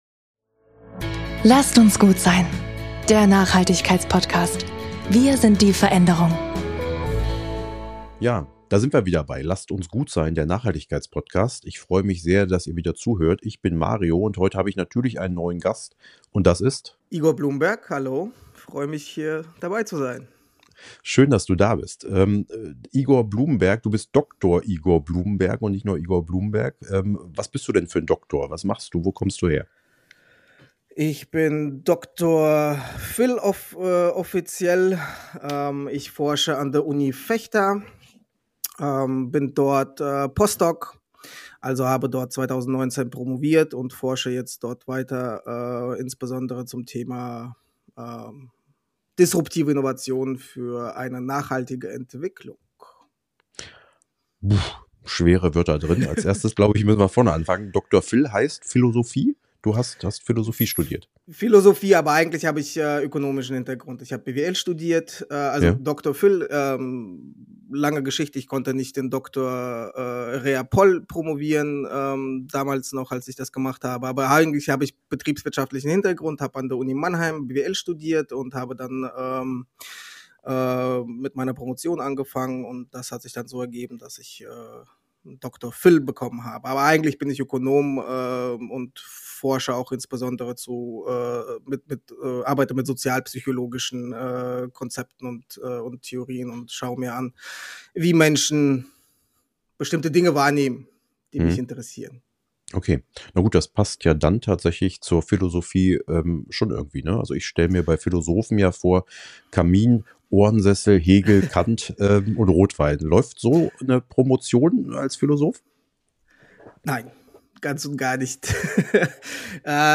Ein Gespräch über Innovation, Ethik und die Zukunft unseres Essens.